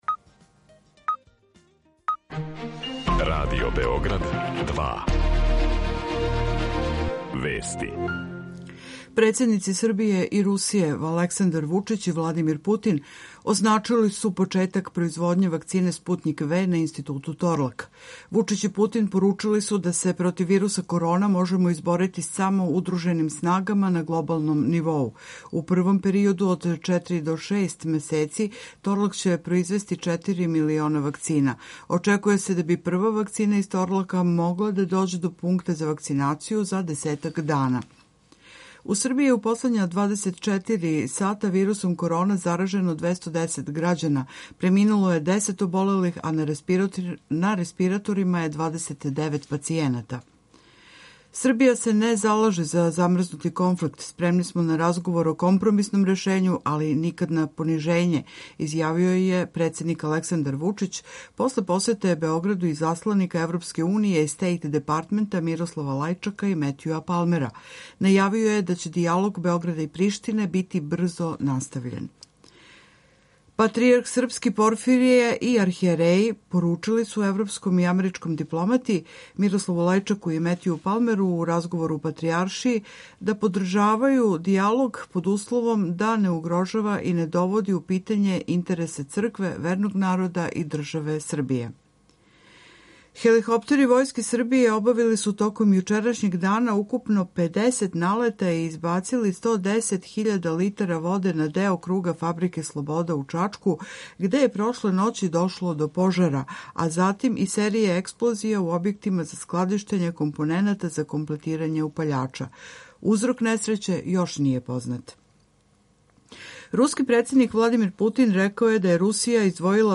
Гост емисије, господин Мирсад Ђерлек, државни секретар у Министарству здравља у Влади Републике Србије.